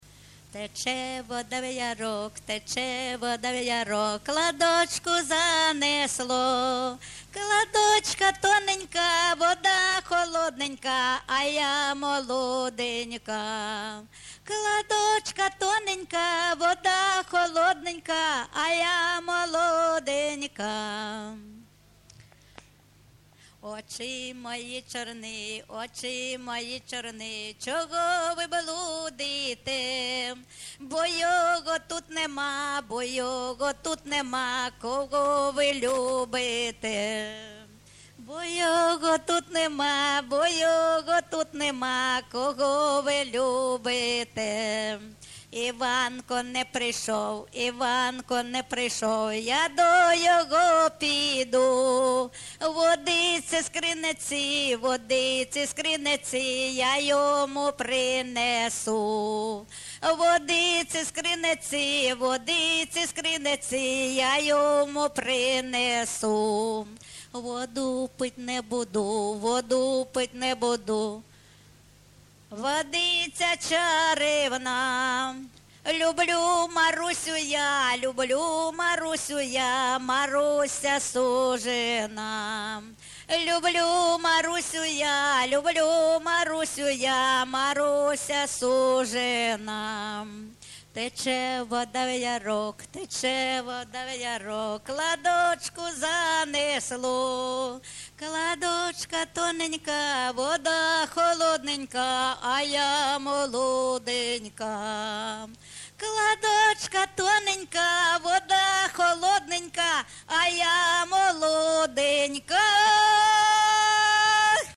ЖанрСучасні пісні та новотвори
Місце записус-ще Новодонецьке, Краматорський район, Донецька обл., Україна, Слобожанщина